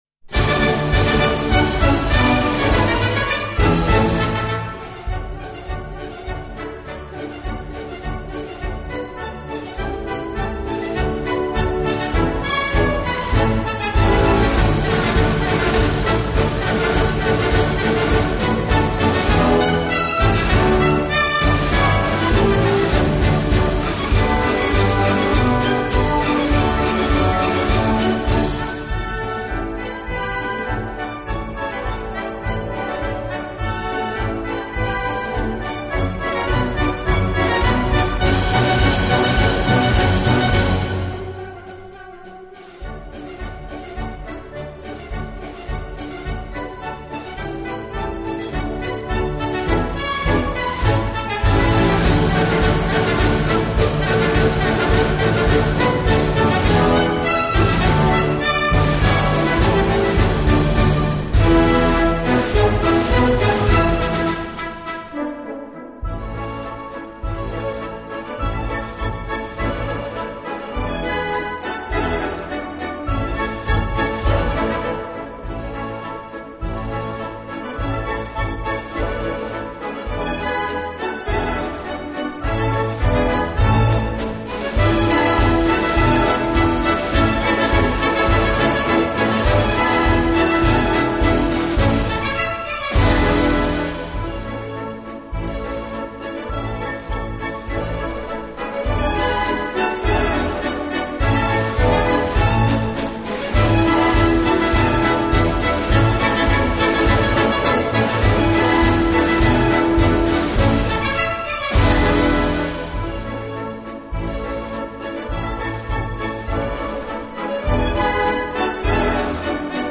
舞曲类别：新年喜庆